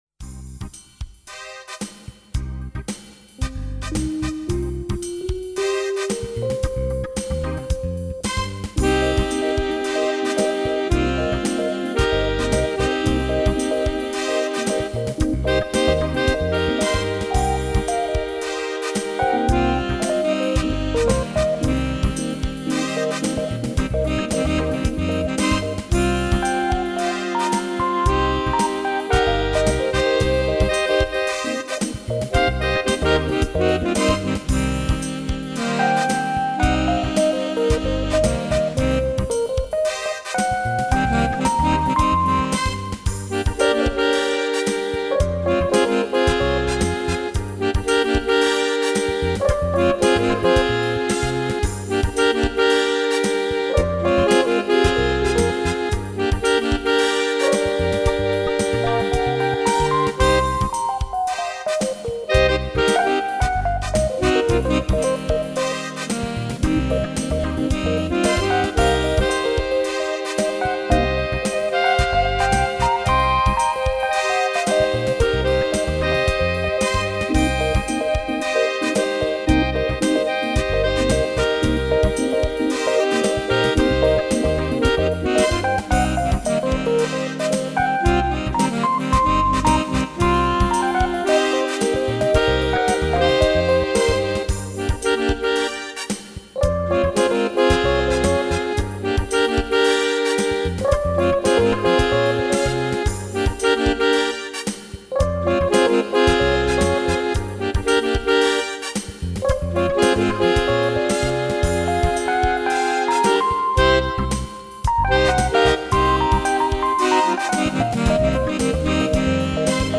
Easy funky atmosphere. Jazzy sax, epiano with rhytm machine.
Tempo: 105 bpm / Date: 26.02.2017